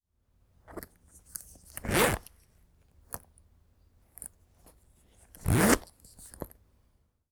Cloth, Rustle